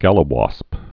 (gălə-wŏsp, -wôsp)